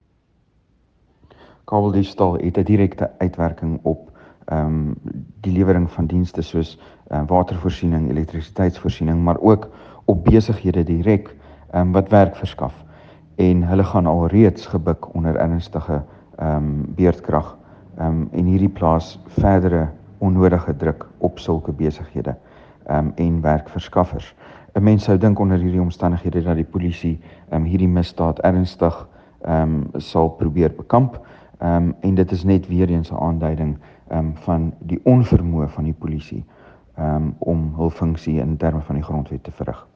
Afrikaans soundbites by George Michalakis MP.